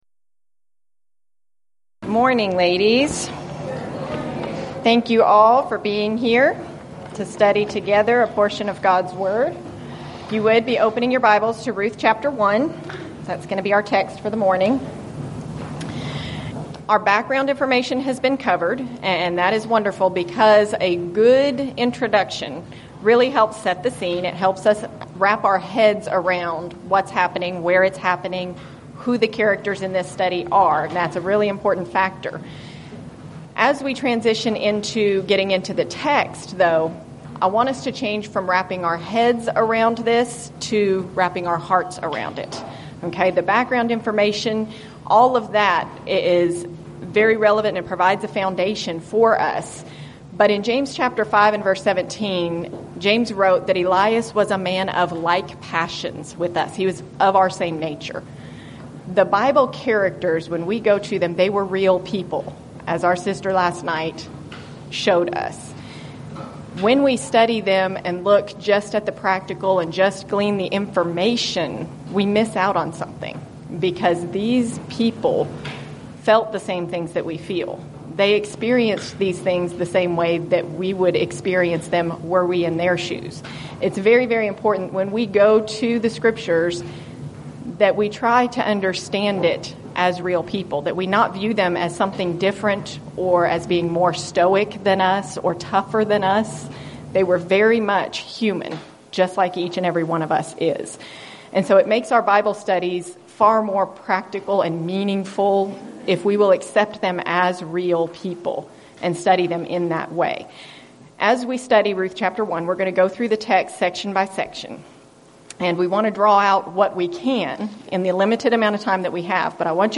Event: 7th Annual Texas Ladies in Christ Retreat
Ladies Sessions